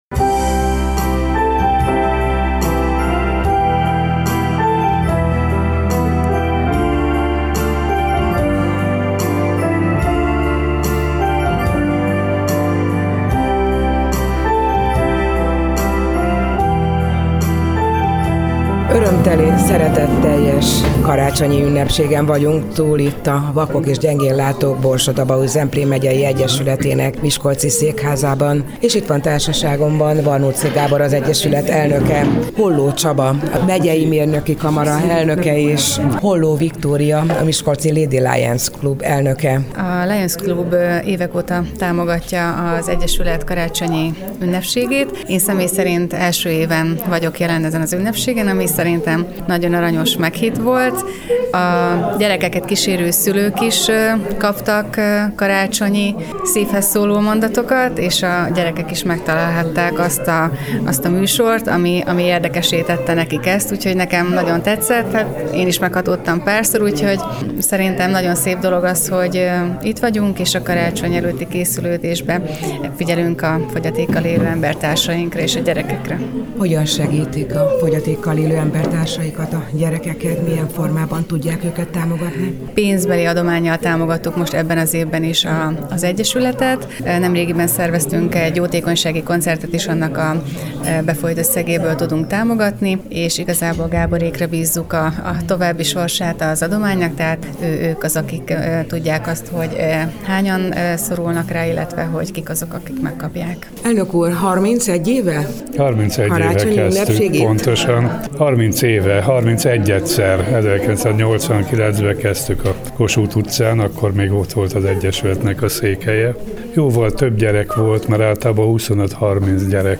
Műsorral és ajándékokkal kedveskedett a látássérült gyermekeknek és szüleinek a Vakok és Gyengénlátók Borsod-Abaúj-Zemplén Megyei Egyesülete. A civil szervezet miskolci székházában megrendezett ünnepségen ellátogatott hozzájuk a Mikulás, akinek kicsik és nagyok lelkesen énekeltek, szavaltak egy-egy ajándékcsomagért cserébe.
_vakok_karacsonya_zenevel.mp3